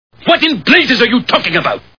The Simpsons [Burns] Cartoon TV Show Sound Bites